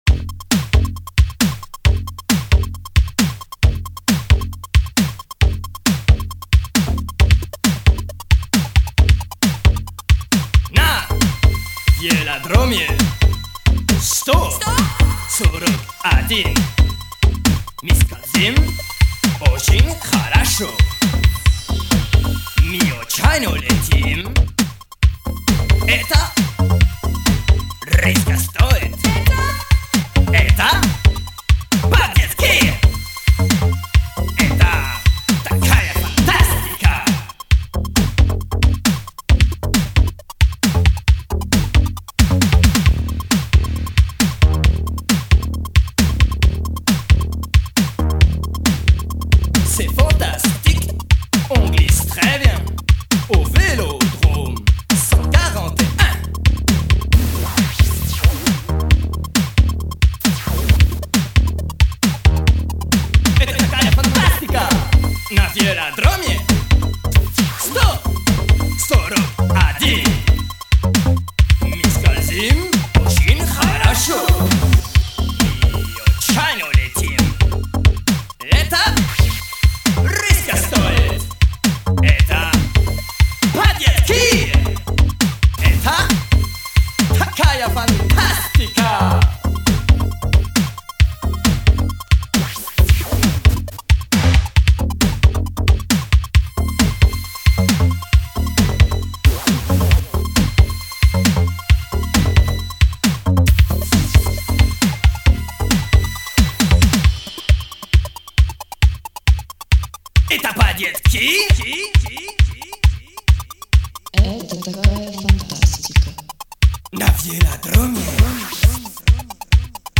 Magnifique pour un lever de soleil en Ardèche.